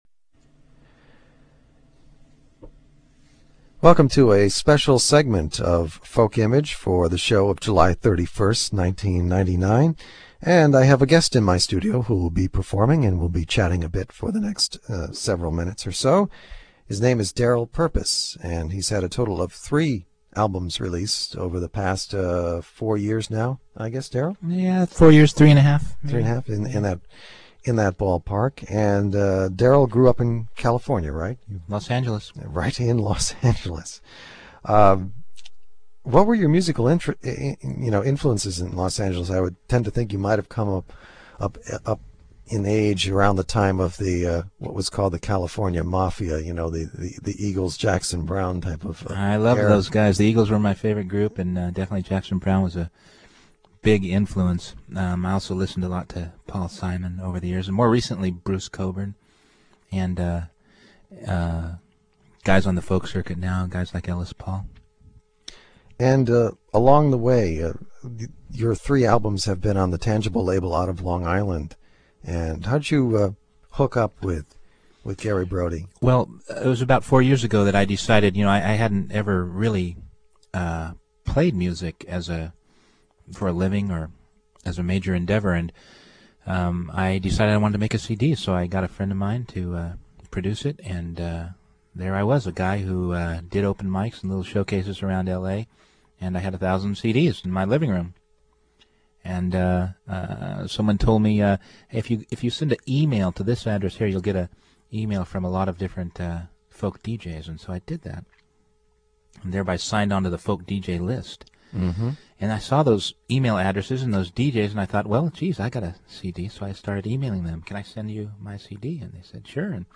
July 1998 interview